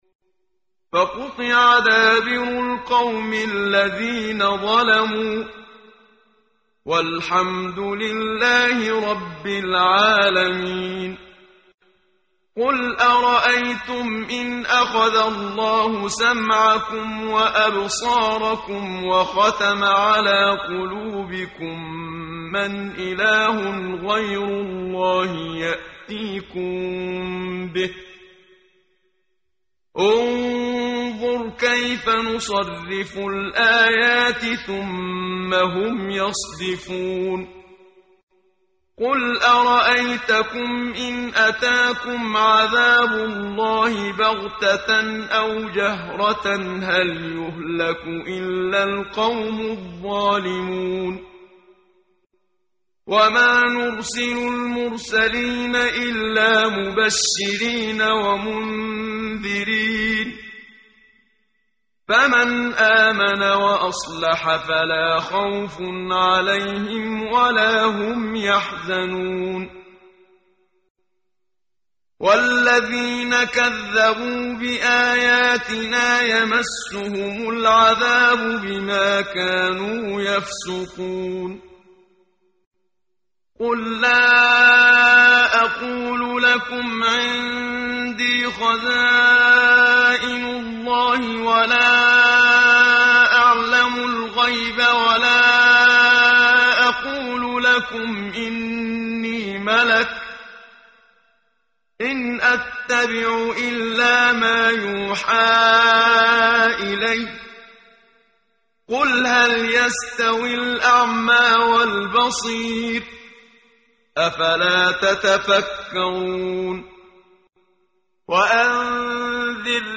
ترتیل صفحه 133 سوره مبارکه انعام (جزء هفتم) از سری مجموعه صفحه ای از نور با صدای استاد محمد صدیق منشاوی